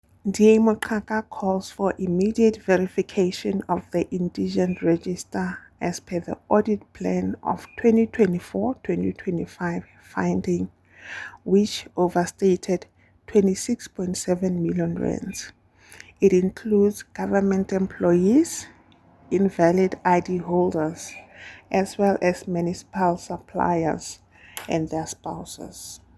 Sesotho soundbites by Cllr Palesa Mpele and